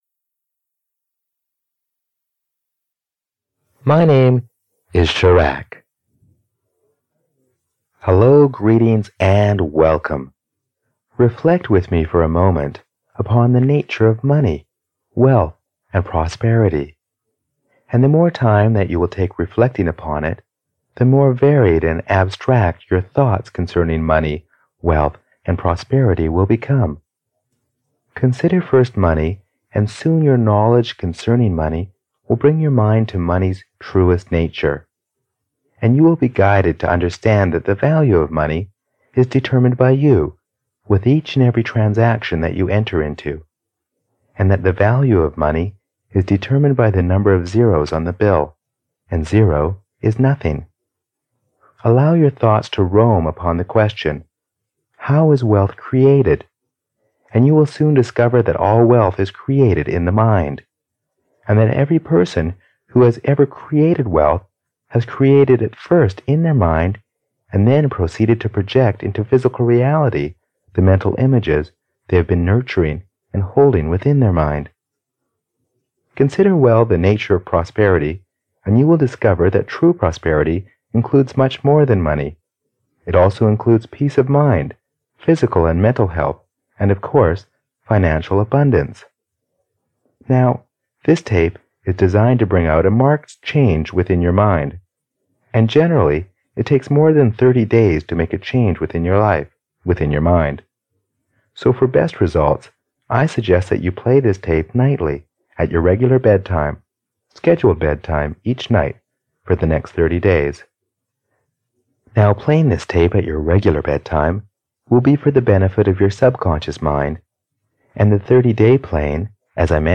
Money and Prosperity 2 (EN) audiokniha
Ukázka z knihy